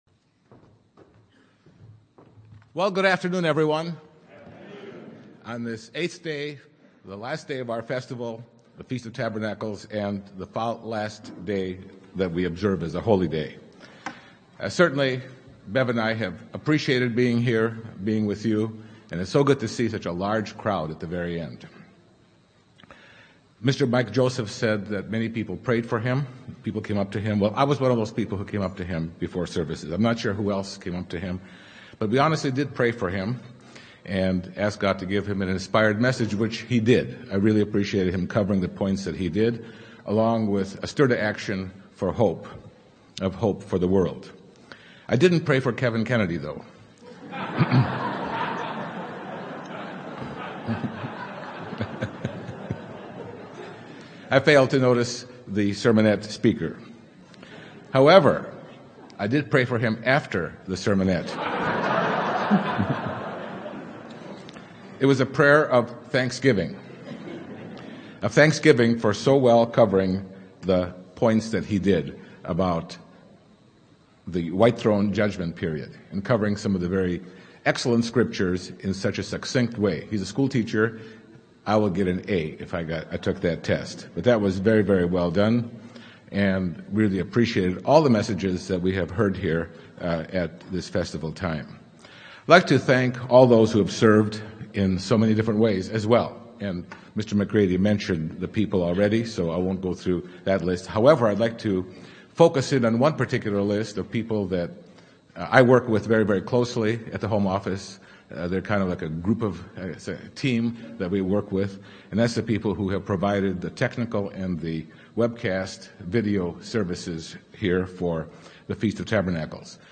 This sermon was given at the Sevierville, Tennessee 2015 Feast site.